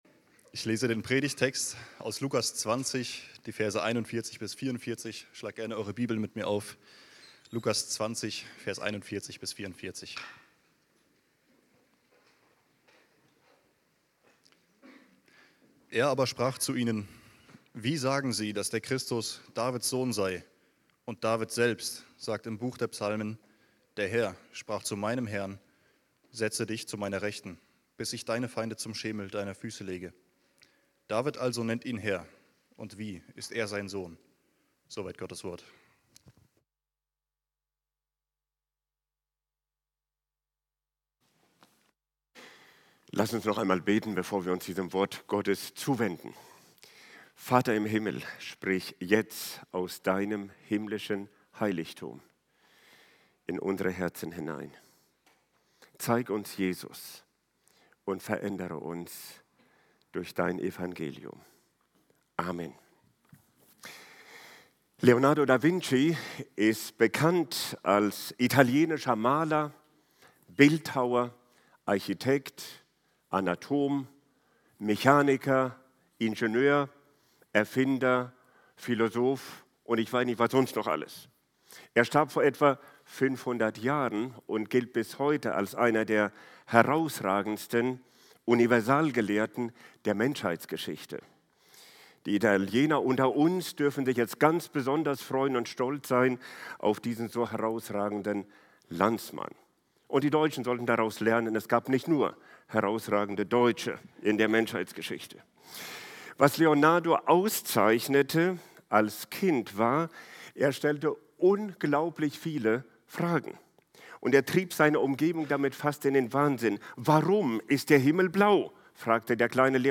** 1. Erkenne die Notwendigkeit biblischer Lehre 2. Staune über das Zentrum biblischer Lehre 3. Erfahre die Wirkung biblischer Lehre Bibeltext: Lukas 20,41-44 Prediger